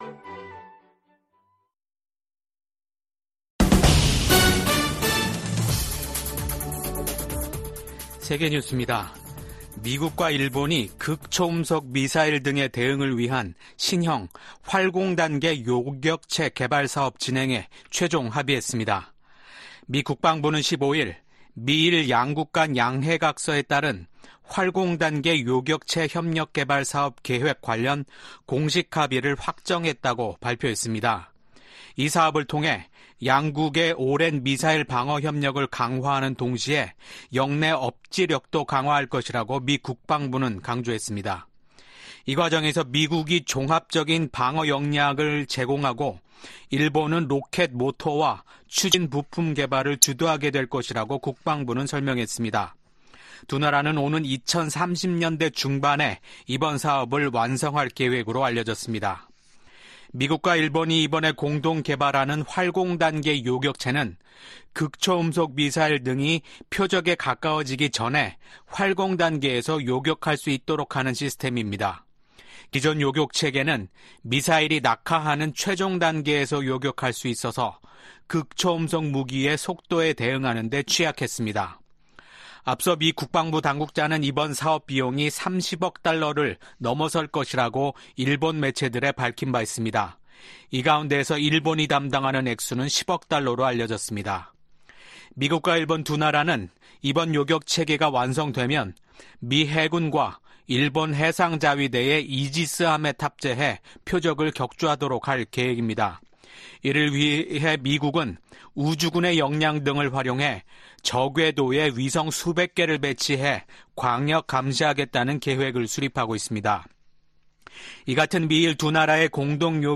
VOA 한국어 아침 뉴스 프로그램 '워싱턴 뉴스 광장' 2024년 5월 17일 방송입니다. 북한이 28년 연속 미국의 대테러 비협력국으로 지정됐습니다. 중국과 러시아가 정상회담을 갖고 전략적 협력 문제를 논의할 예정인 가운데 미국 정부가 양국에 북한 문제 해결에 나설 것을 촉구했습니다.